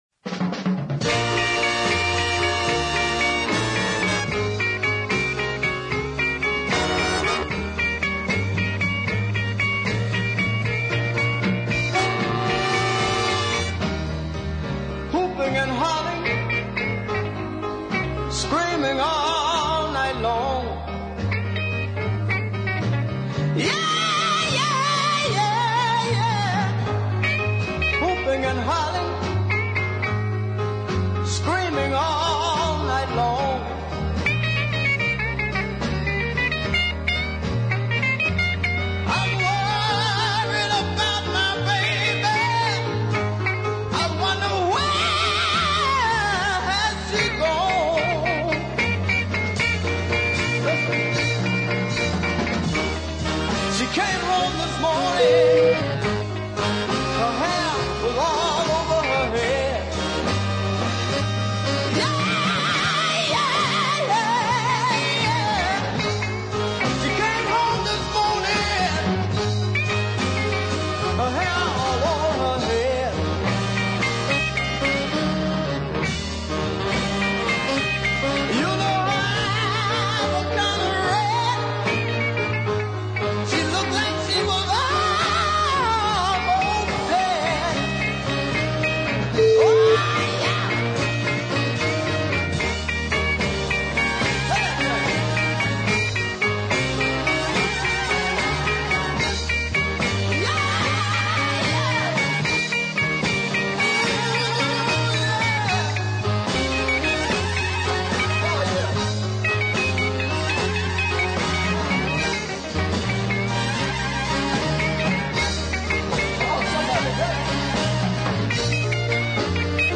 Even better was the celebrated slow blues flip Listen